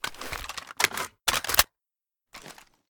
ru556_reloadtac.ogg